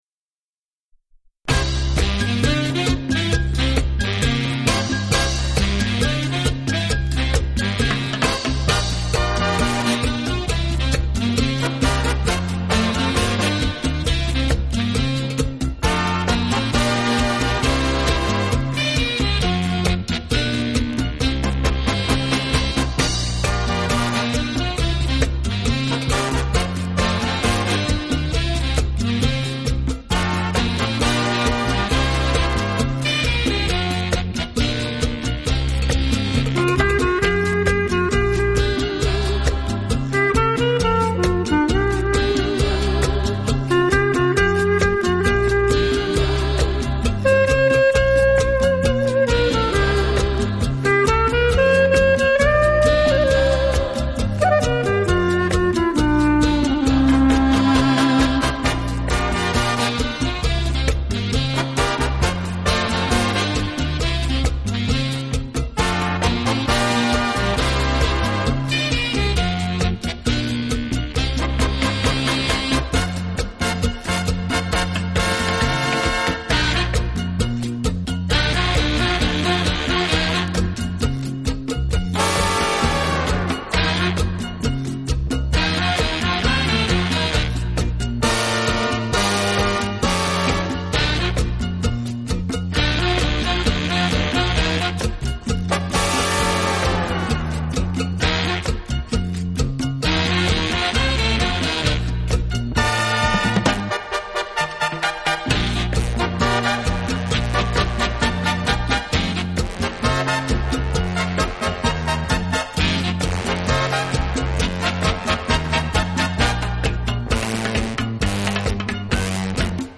【轻音乐】
Cha-cha-cha 33